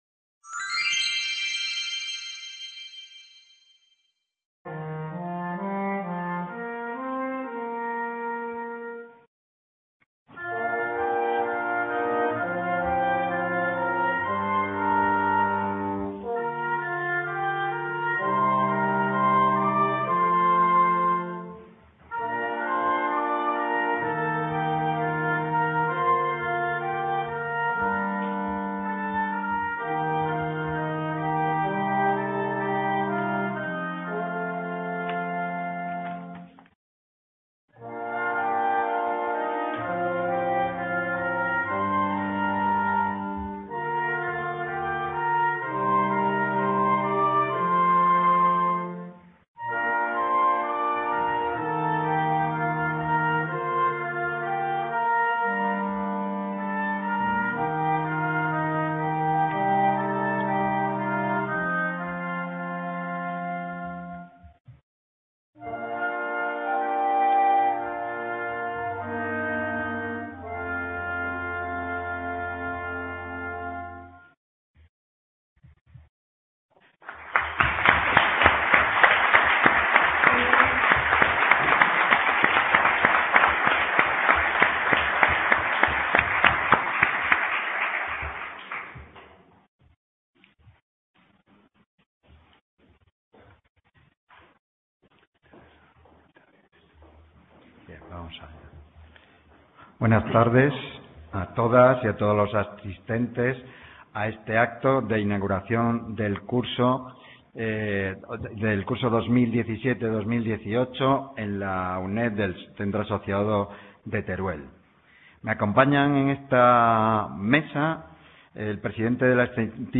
Celebración oficial apertura del curso académico 2017/18 CA Teruel